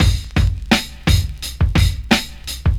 Free drum groove - kick tuned to the F# note. Loudest frequency: 1296Hz
86-bpm-00s-drum-loop-f-sharp-key-riT.wav